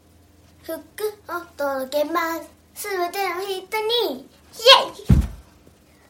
リズミカル大賞！ なかなか凝ったリズムで、何度も聴き返してしまいます。 かっこいいナンバーですねっ